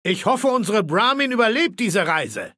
Datei:Maleold01 ms06 greeting 00027f95.ogg
Fallout 3: Audiodialoge